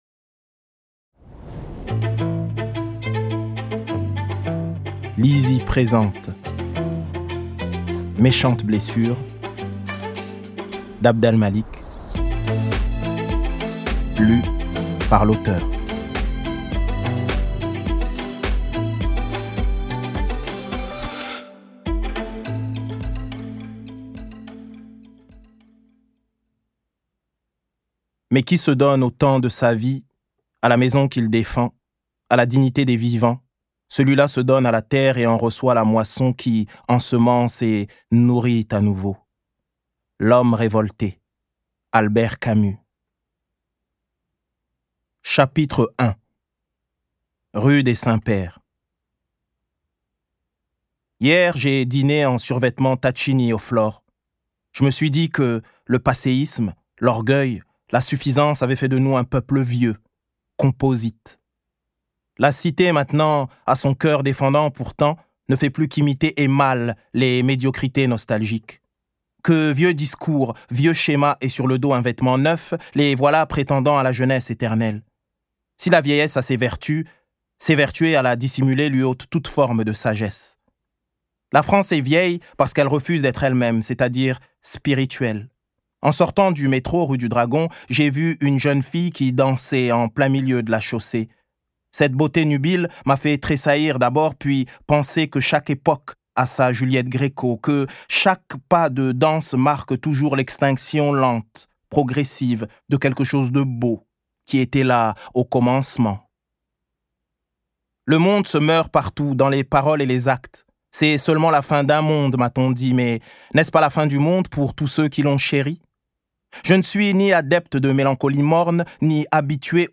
Click for an excerpt - Méchantes blessures de Abd al Malik
Méchantes blessures raconte la vie et la mort de ce Français du XXIe siècle. Dans ce récit subtilement tissé, entre roman noir, conte philosophique et spirituel, Abd al Malik imprègne durablement l'esprit et transmet par son écriture sa vision d'un avenir commun. un récit à la première personne parfaitement construit et maistrisé qui trouve toute sa profondeur dans la voix de l'auteur.